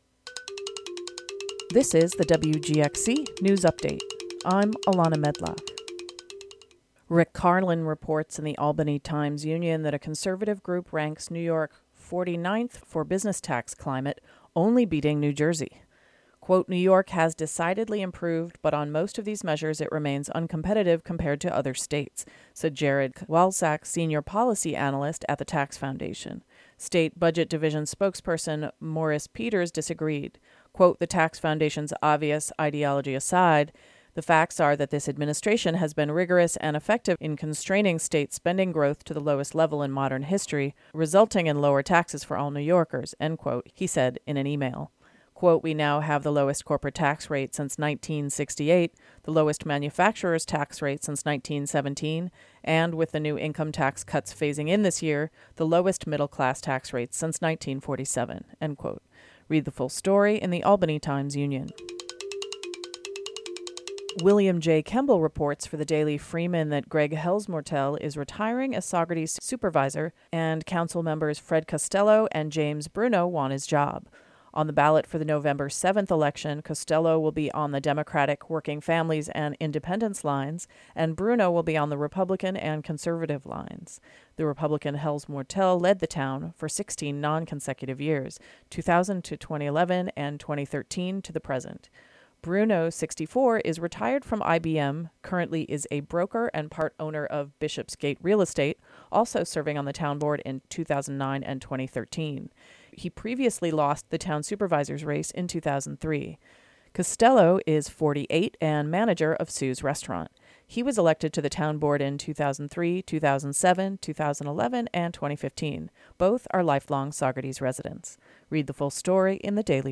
WGXC Local News Audio Link